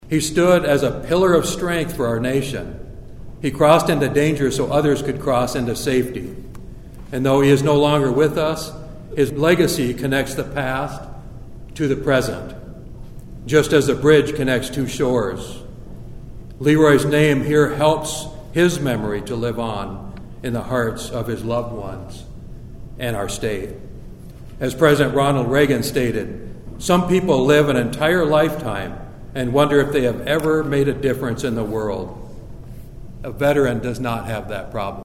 ONIDA, (KCCR) — Students and residents filled the Sully Buttes High School gym Wednesday afternoon to honor a local Fallen Hero by dedicating a bridge in his name.